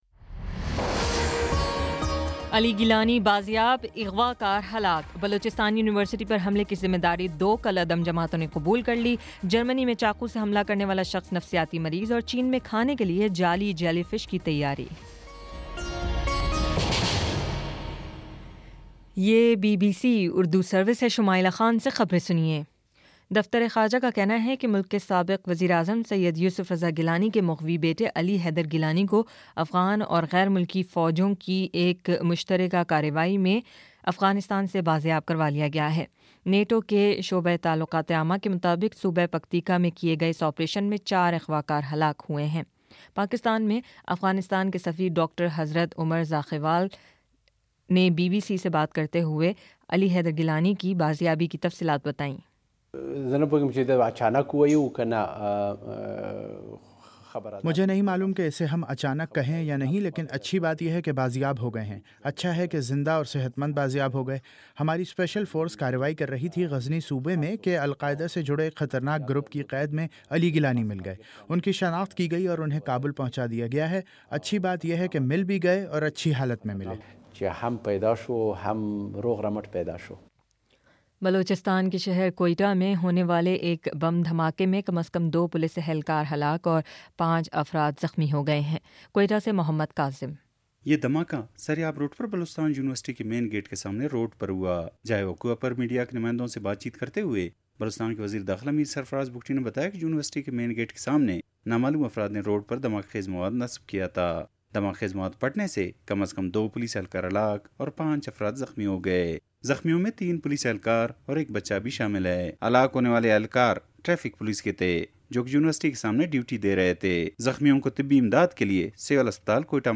مئی 10 : شام چھ بجے کا نیوز بُلیٹن